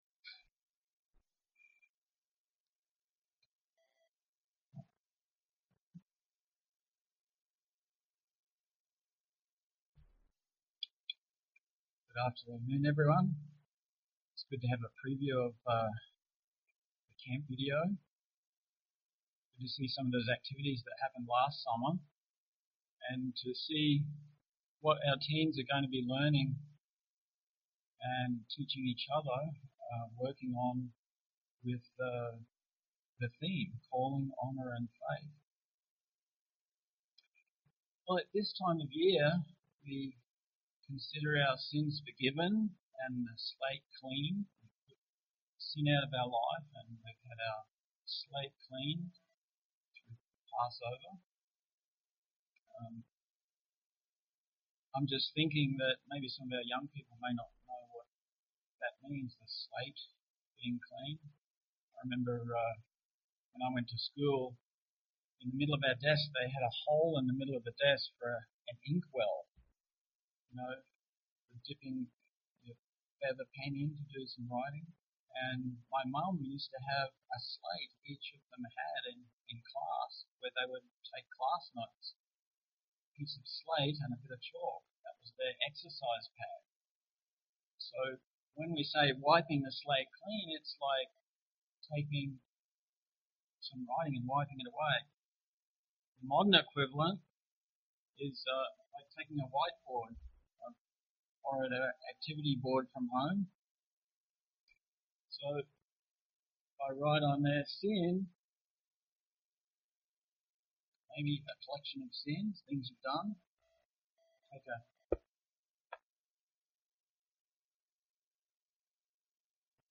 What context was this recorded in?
Given in Twin Cities, MN